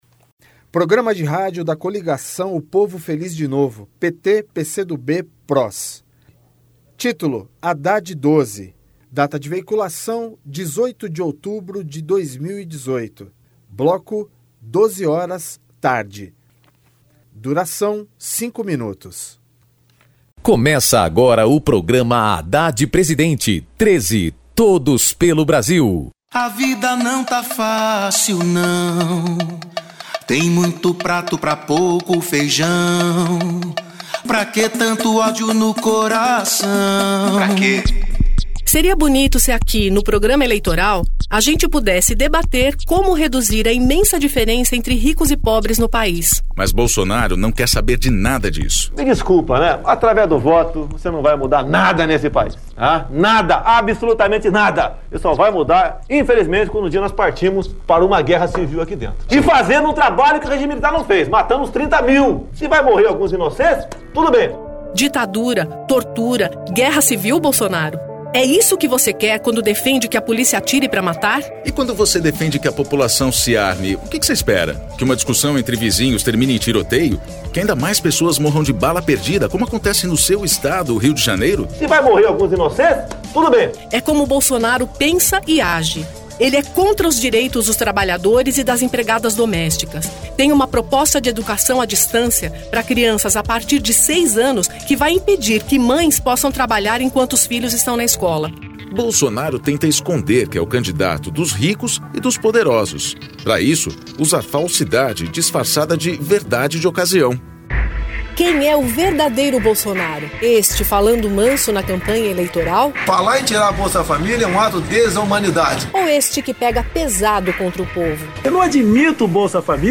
Gênero documentaldocumento sonoro
Descrição Programa de rádio da campanha de 2018 (edição 42). 2º Turno, 18/10/2018, bloco 12hrs.